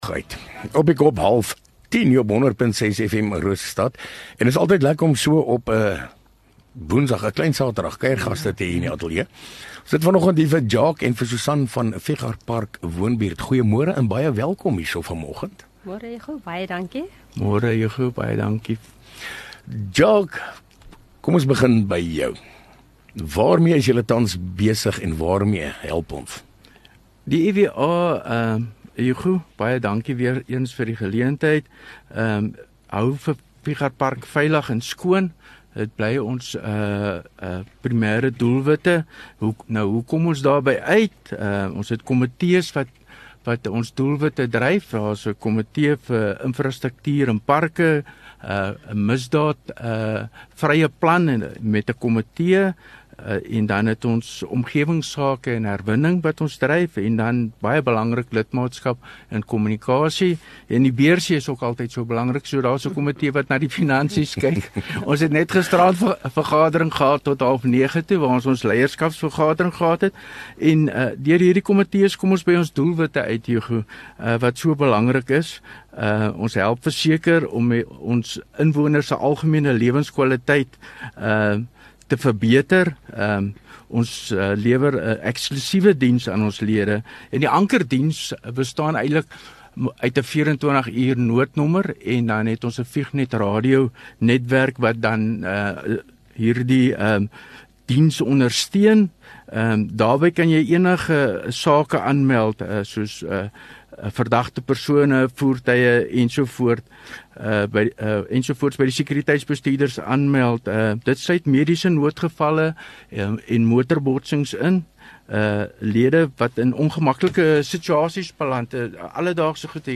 View Promo Continue Radio Rosestad Install Gemeenskap Onderhoude 21 Aug Fichardtpark woonbuurt